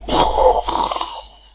enem_crooked_dead1.wav